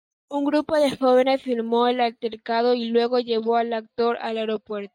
al‧ter‧ca‧do
/alteɾˈkado/